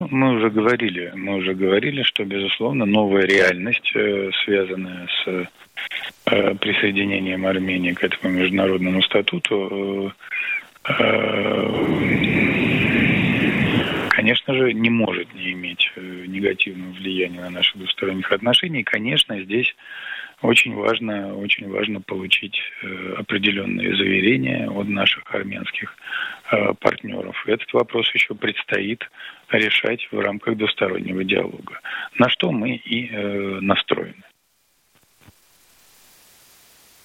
«Հռոմի ստատուտին Հայաստանի միանալը նոր իրողություն է ստեղծել։ Չի կարող բացասական ազդեցություն չունենալ»,- Պուտինի՝ Հայաստան այցի մասին TACC-ի հետ զրույցում նշել է Դմիտրի Պեսկովը:
«Միջազգային այդ կառույցին Հայաստանի միանալը, իհարկե, չի կարող բացասական ազդեցություն չունենալ մեր երկկողմ հարաբերությունների վրա։ Իհարկե, այստեղ շատ կարևոր է որոշակի երաշխիքներ ստանալ մեր հայ գործընկերներից։ Այդ հարցը դեռ պետք է լուծվի երկկողմ երկխոսության շրջանակում, ինչին մենք տրամադրված ենք», – ասել է Կրեմլի խոսնակը։